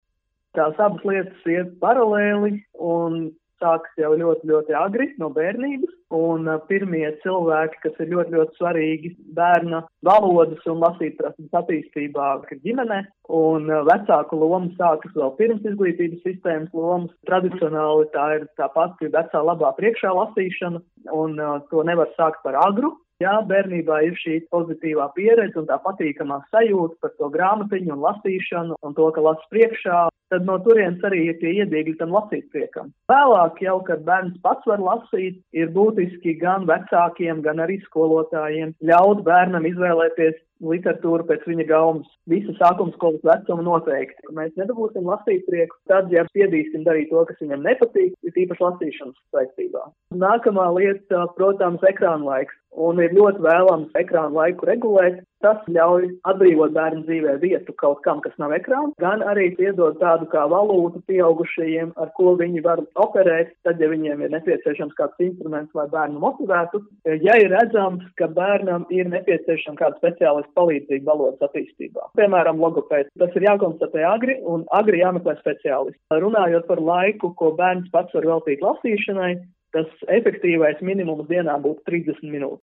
SKONTO MEDIJU GRUPAS ziņas